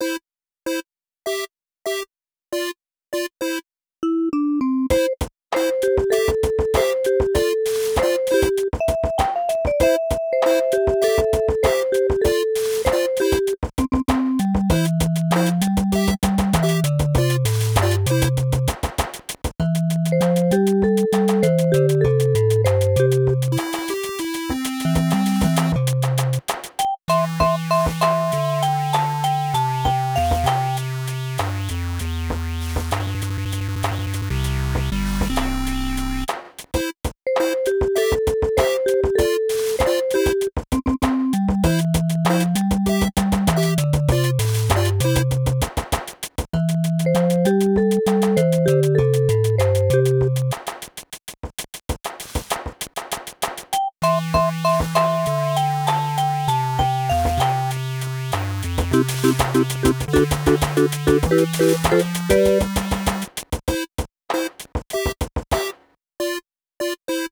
This was supposed to be an introduction to sequencing with the keyboard and was pretty open reign. I wanted to create something kind of playful and I think this came out that way, though the timings on some of the notes could use some revision.